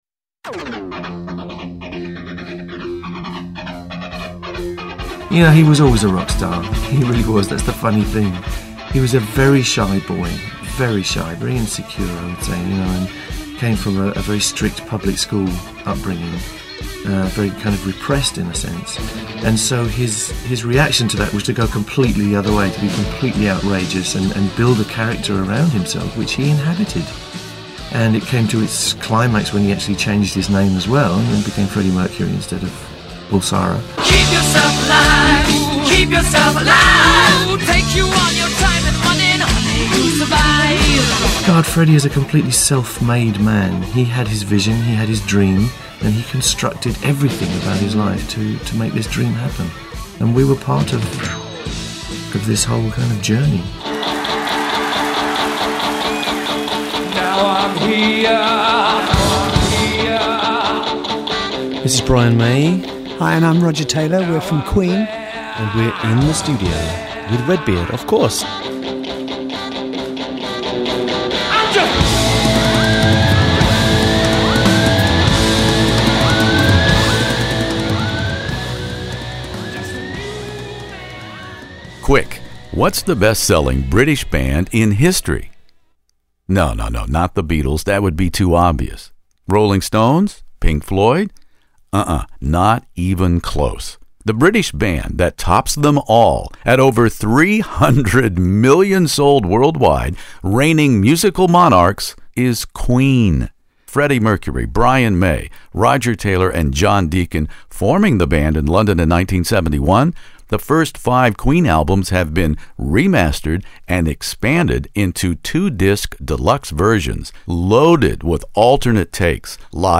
Queen earliest days interview with Brian May and Roger Taylor In the Studio